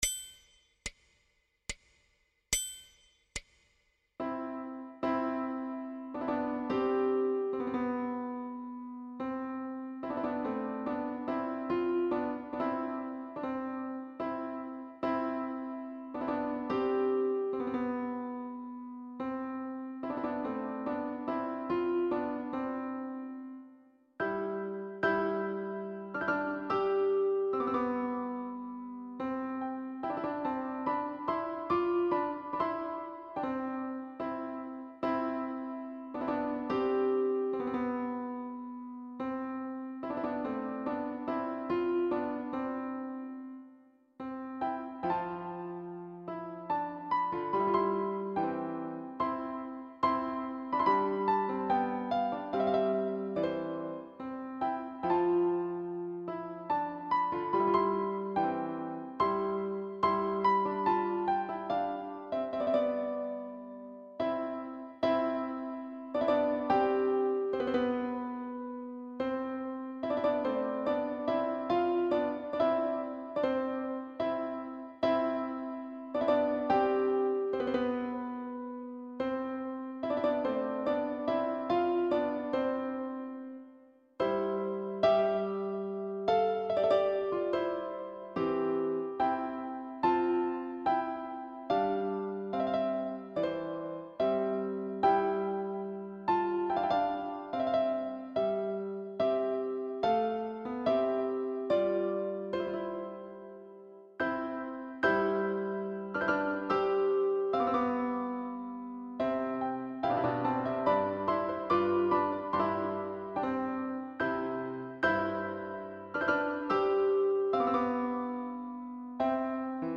Without Pianist 2